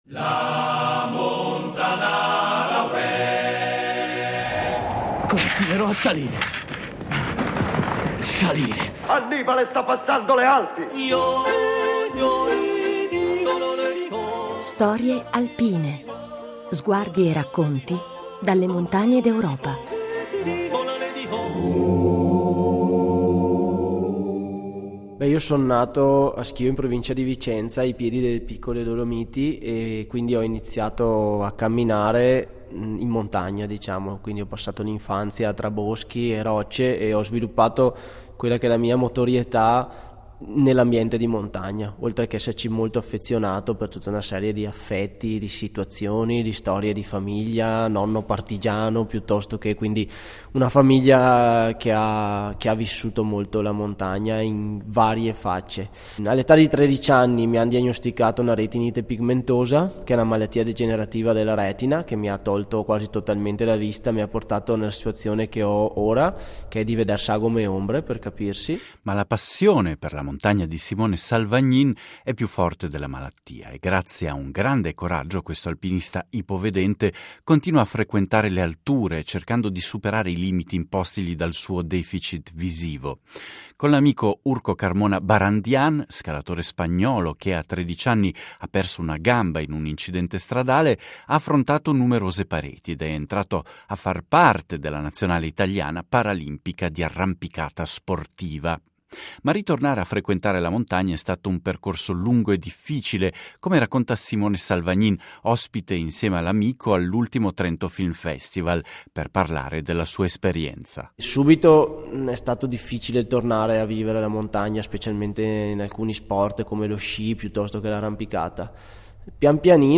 Riflessioni sulle Alpi raccolte al Film Festival della Montagna di Trento